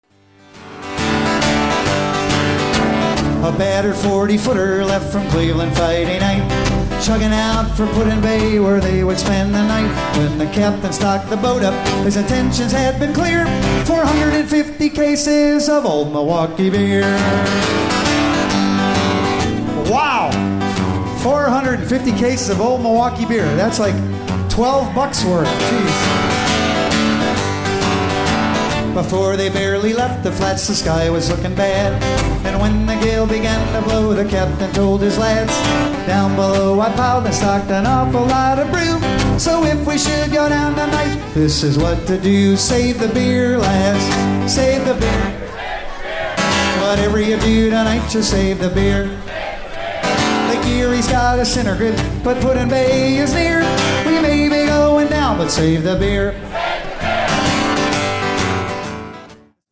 guitar and banjo
including some live recordings